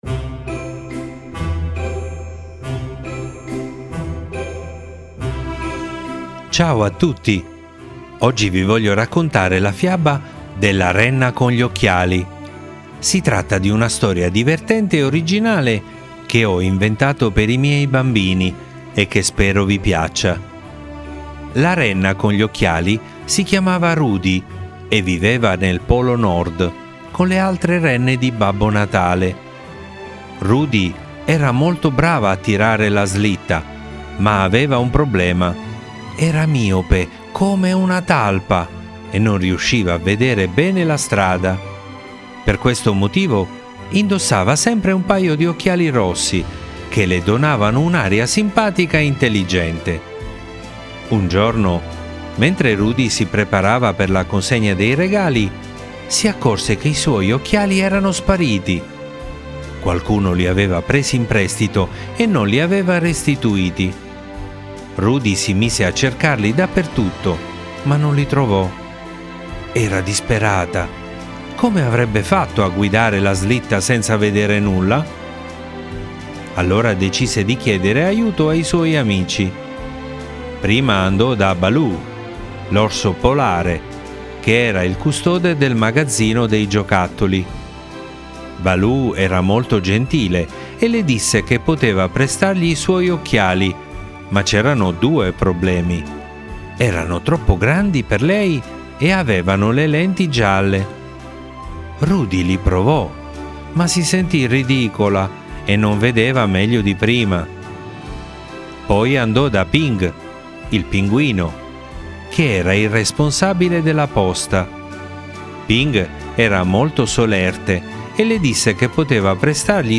Le favole della buonanotte